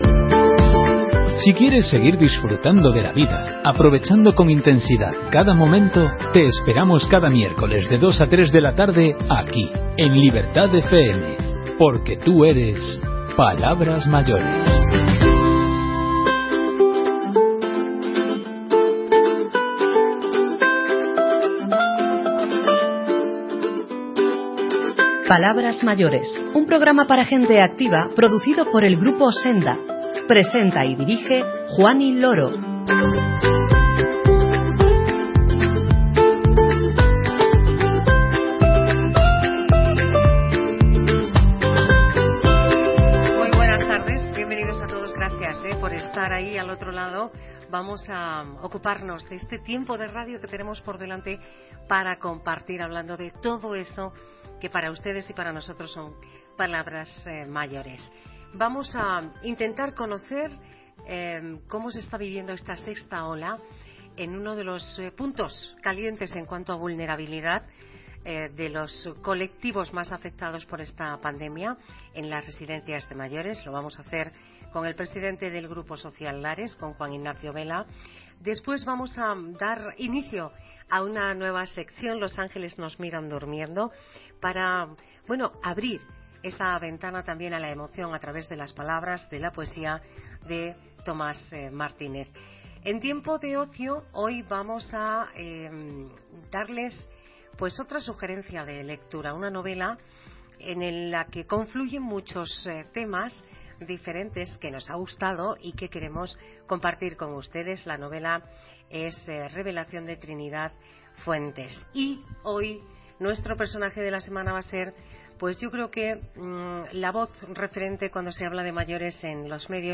Residencias ante la sexta ola y comunicación sobre mayores, estos son los protagonistas del programa emitido en Libertad FM